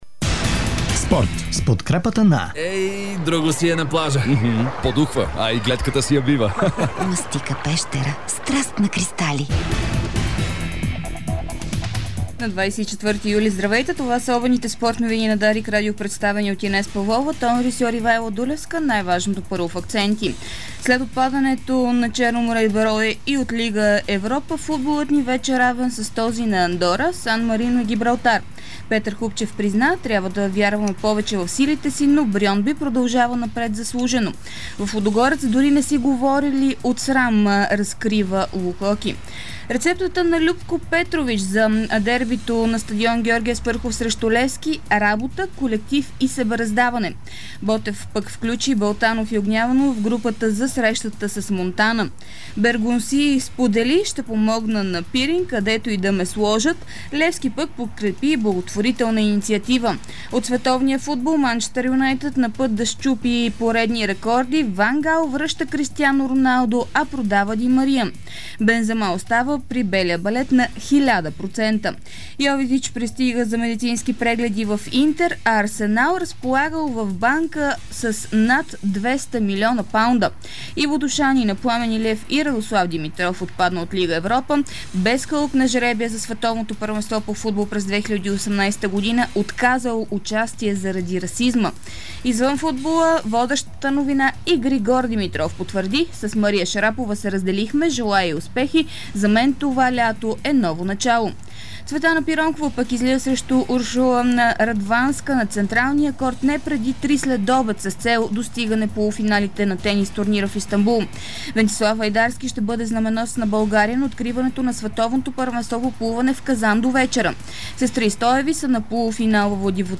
в студиото на "Гонг"